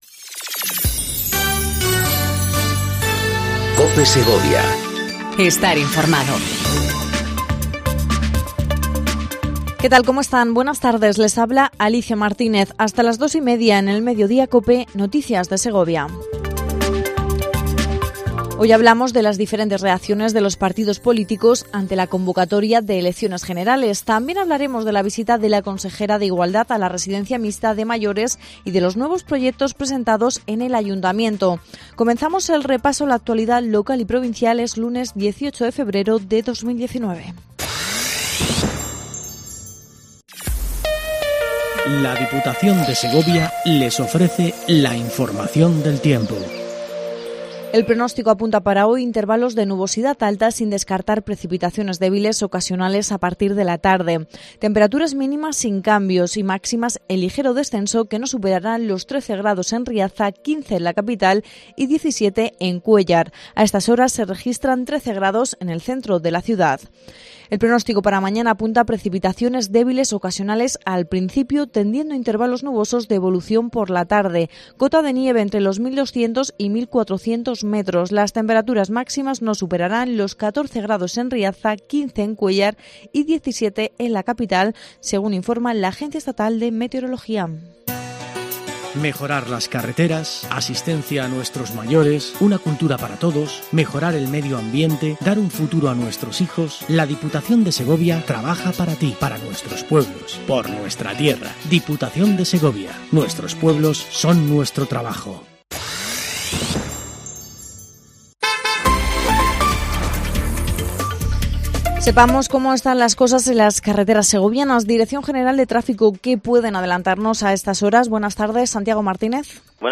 INFORMATIVO DEL MEDIODÍA EN COPE SEGOVIA 14:20 DEL 18/02/19